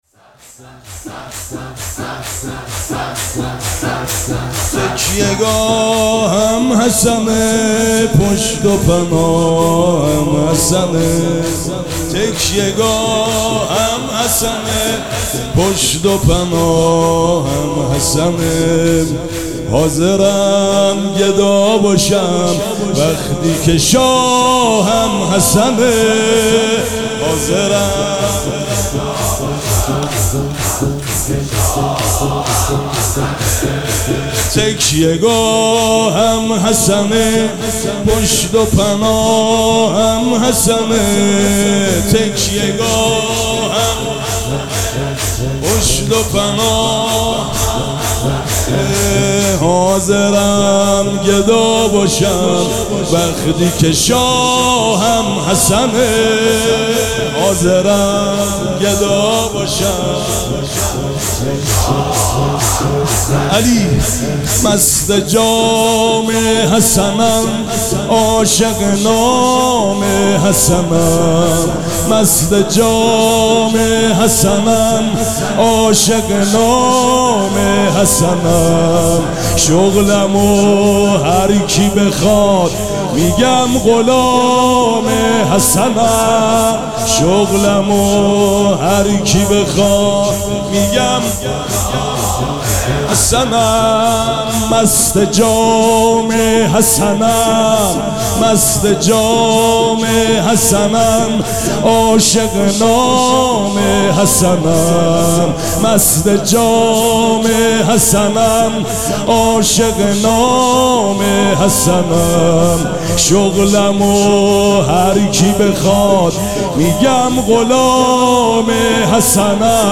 مراسم عزاداری شب شهادت امام حسن مجتبی(ع)
شور